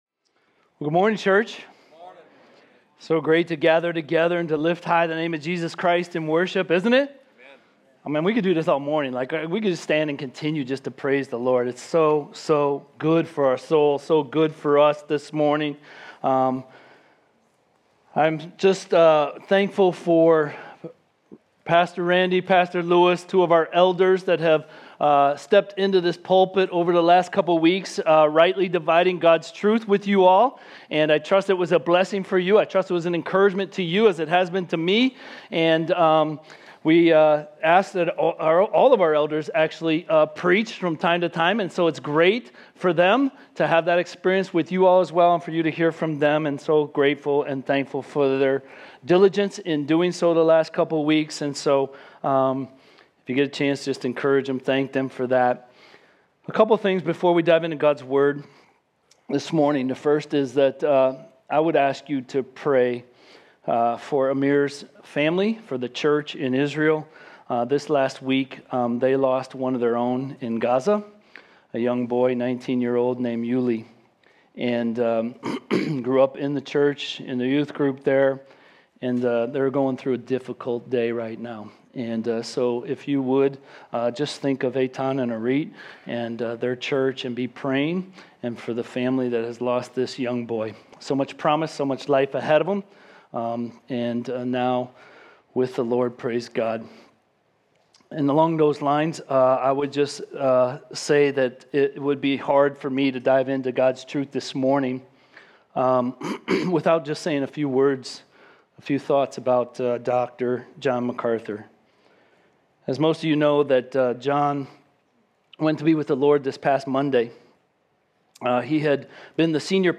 Home Sermons Summer Psalms 2025